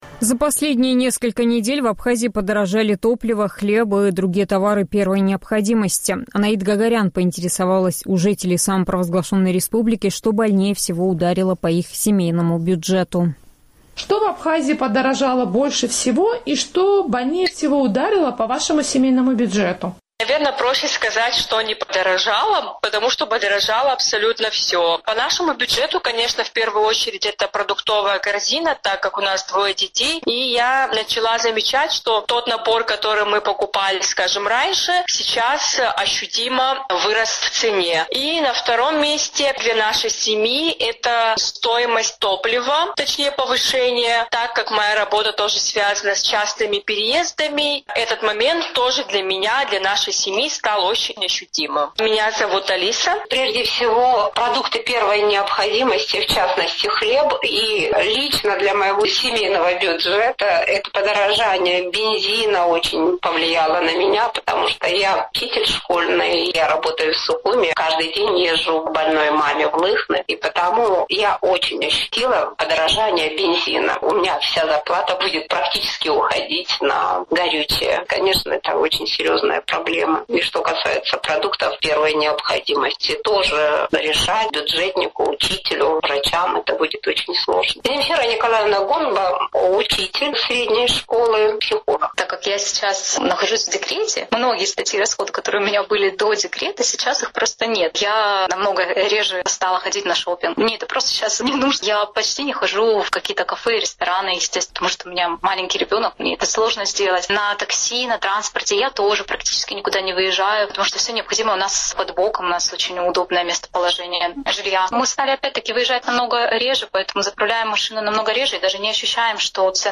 За последние несколько недель в Абхазии наблюдается рост цен на топливо, хлеб и другие товары первой необходимости. «Эхо Кавказа» поинтересовалось у жителей республики, что, на их взгляд, подорожало больше всего и что больнее всего ударило по их семейному бюджету.